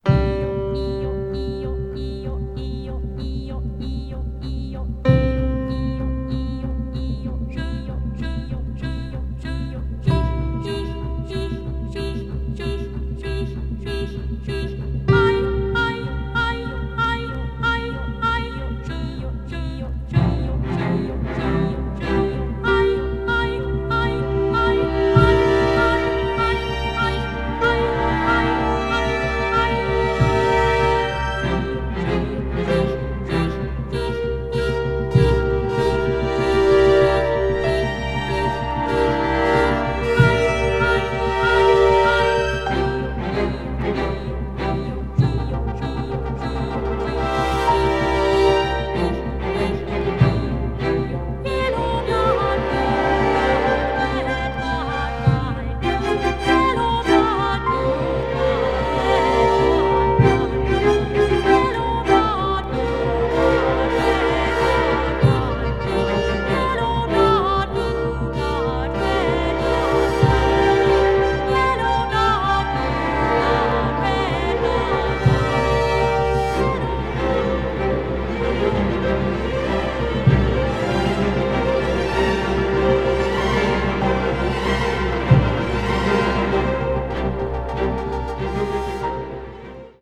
media : EX+/EX+,EX+/EX+(わずかにチリノイズが入る箇所あり)